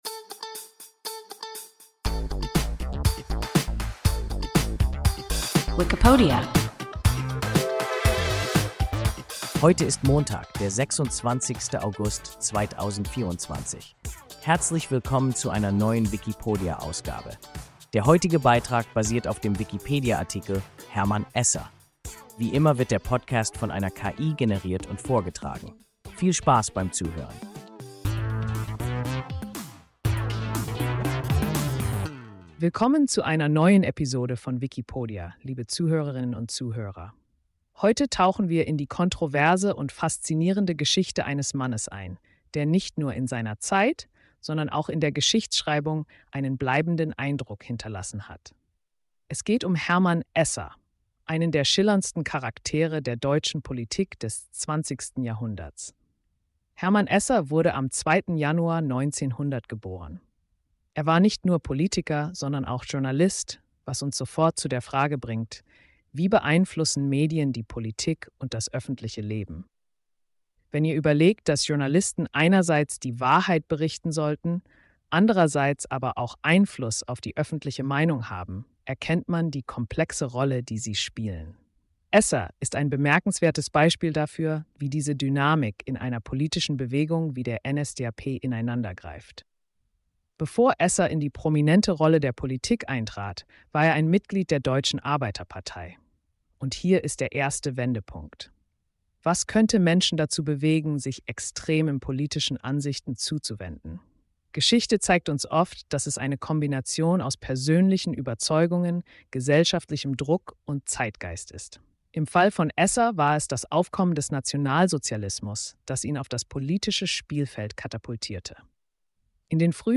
Hermann Esser – WIKIPODIA – ein KI Podcast